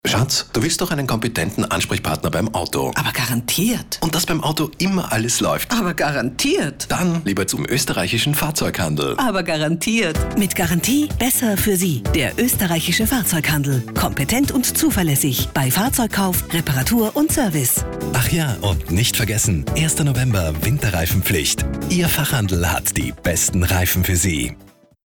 Fahrzeughandel: Neuer Spot im Radio | AUTO & Wirtschaft
Im Herbst 2016 wird der neue Radiospot des Fahrzeughandels über den Äther geschickt, bei uns können Interessierte die Werbeeinschaltung bereits jetzt hören.